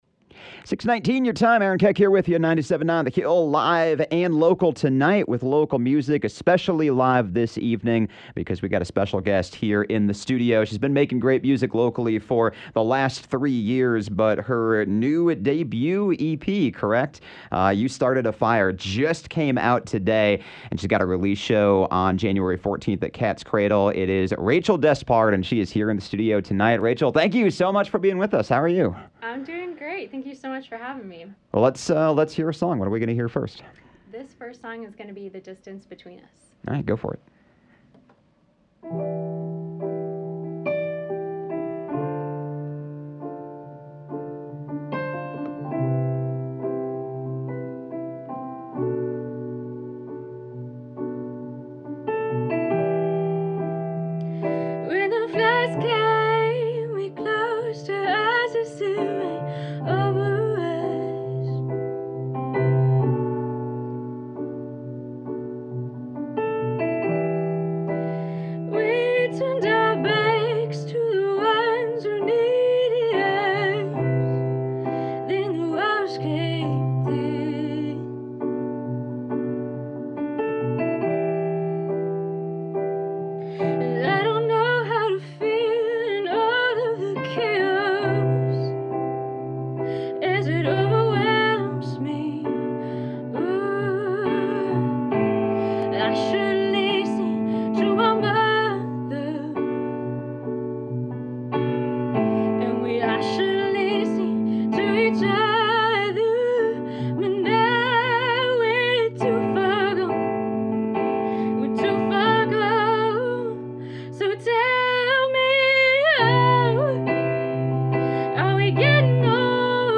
singer-songwriter
blends folk with soul